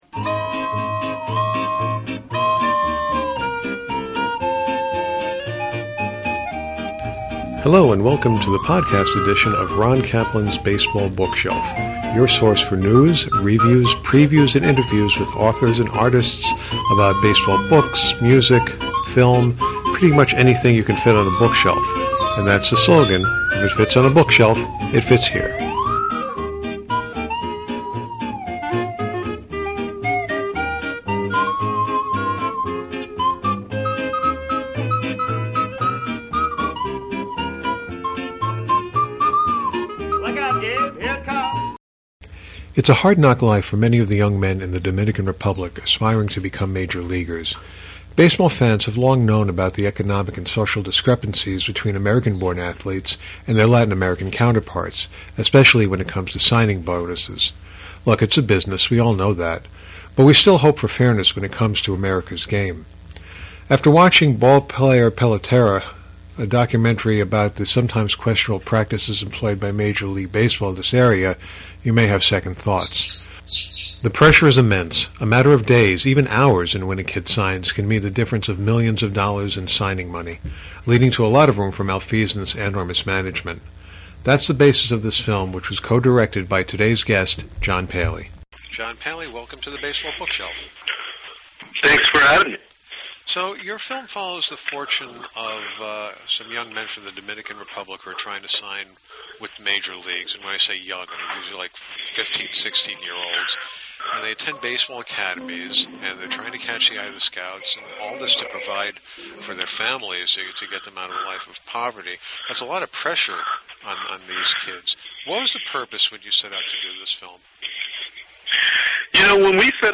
baseball documentary